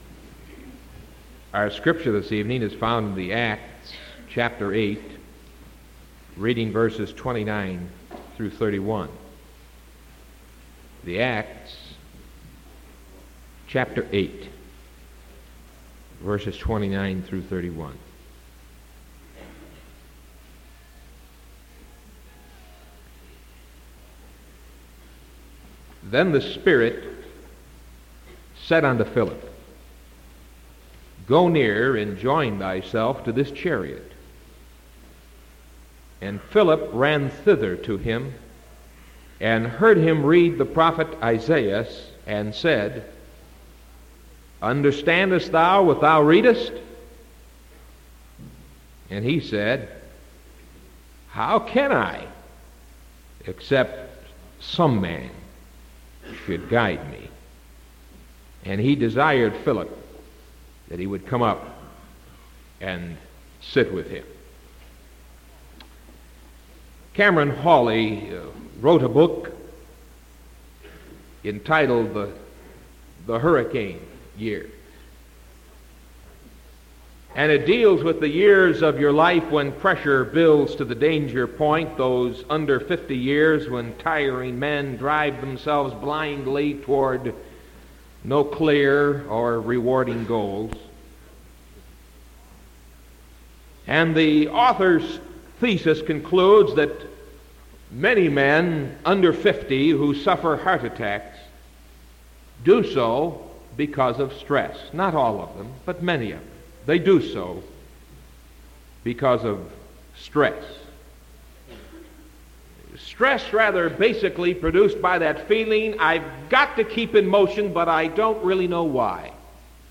Sermon March 17th 1974 PM